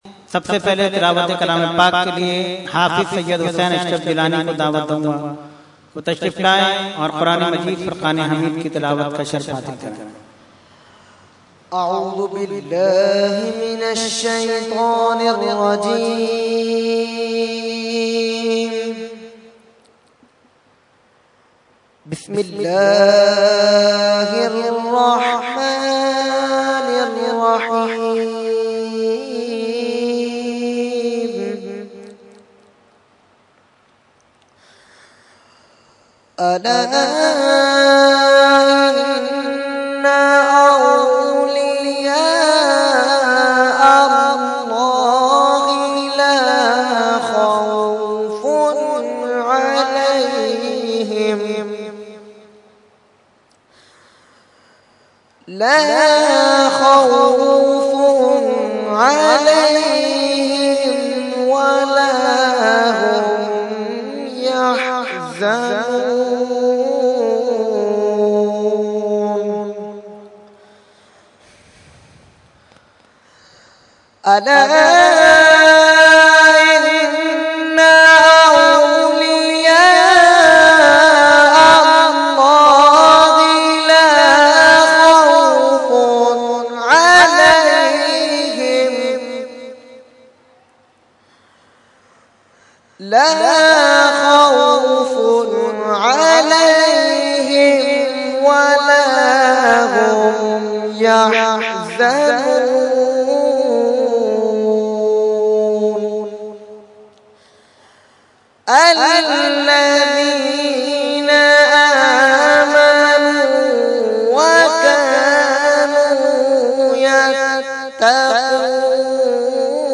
Qirat – Urs Ashraful Mashaikh 2014 – Dargah Alia Ashrafia Karachi Pakistan